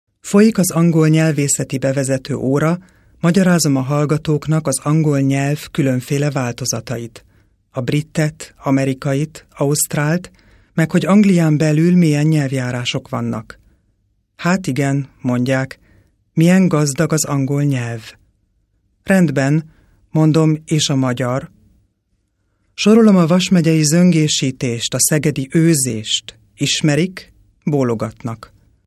Kein Dialekt
Sprechprobe: Industrie (Muttersprache):
hungarian female voice over artist